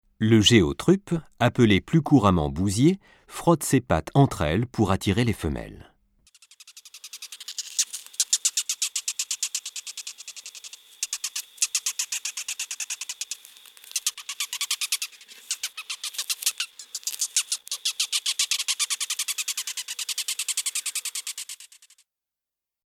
geotrupe.mp3